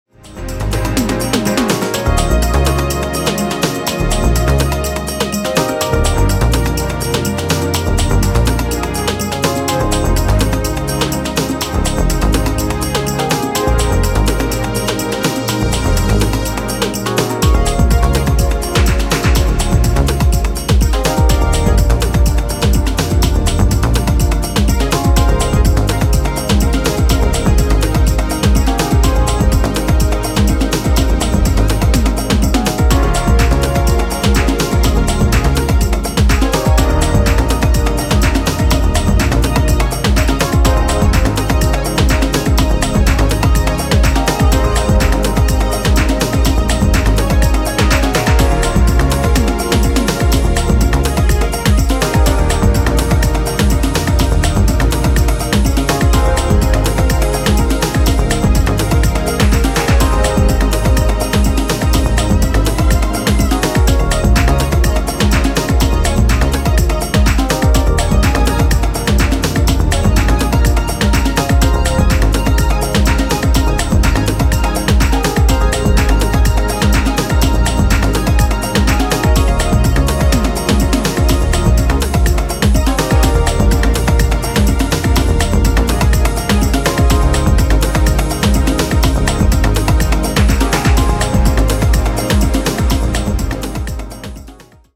closes the record with its trance inducting romantic vibes